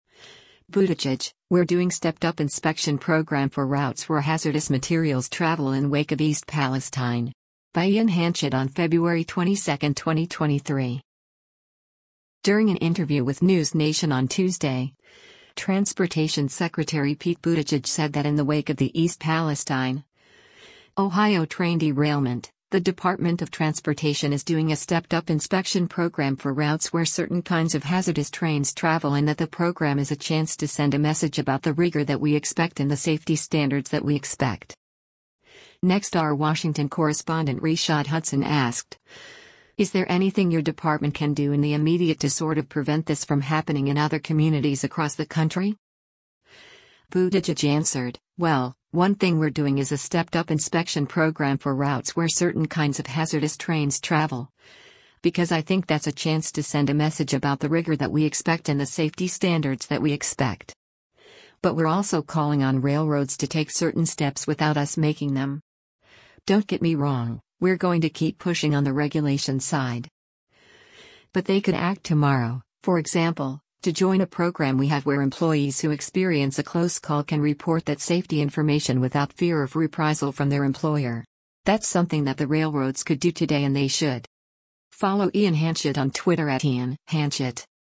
During an interview with NewsNation on Tuesday, Transportation Secretary Pete Buttigieg said that in the wake of the East Palestine, Ohio train derailment, the Department of Transportation is doing “a stepped-up inspection program for routes where certain kinds of hazardous trains travel” and that the program is “a chance to send a message about the rigor that we expect and the safety standards that we expect.”